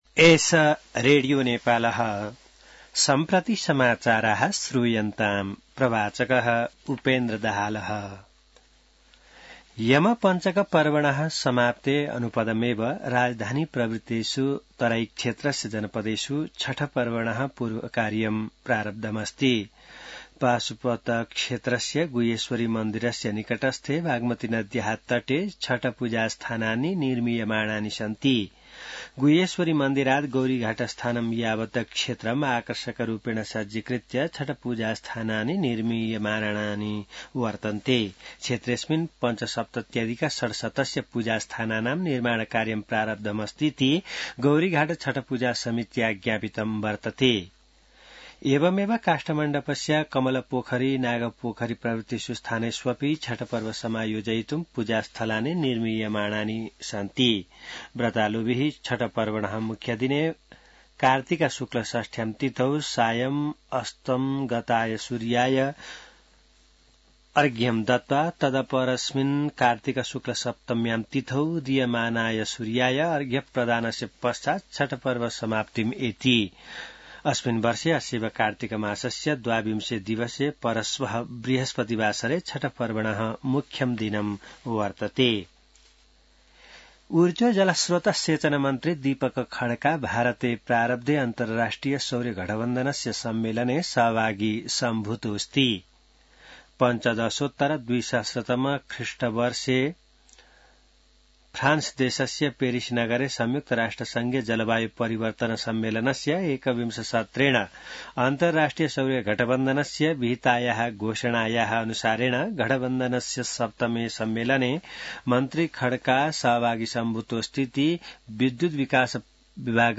संस्कृत समाचार : २१ कार्तिक , २०८१